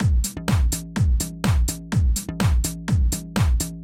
Drumloop 125bpm 06-A.wav